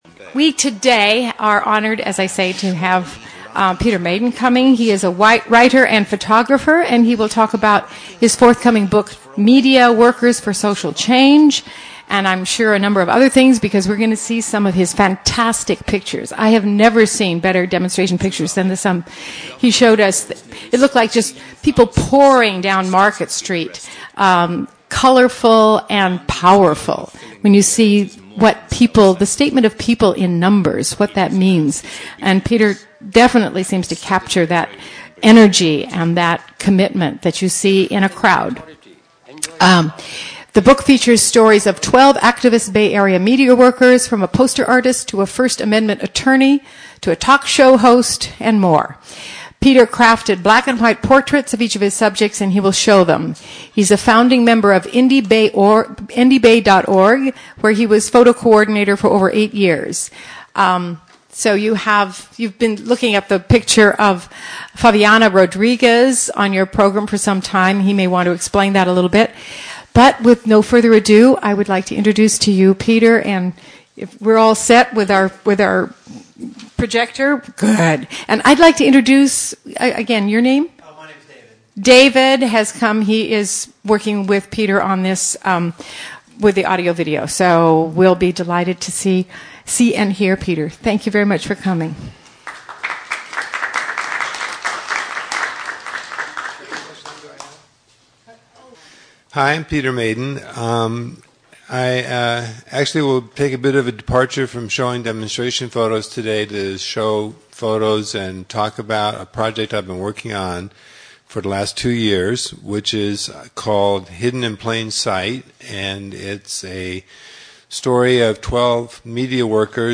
was invited to speak about his still-ongoing project at the weekly Breakfast Forum at the First Unitarian Universalist Church in San Francisco.